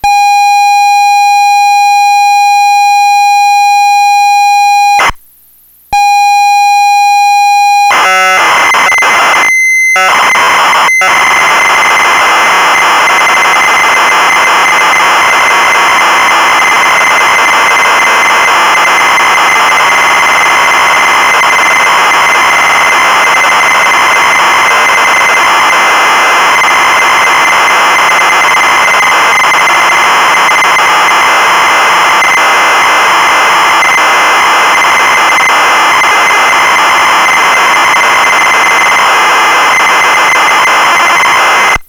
Renumber Program in Basic (MP-3) using Line Numbers 16290 to 16370 includes Serial Driver for MC68B50 in Lines 1, 2 and 8 (Line 9 Can be deleted.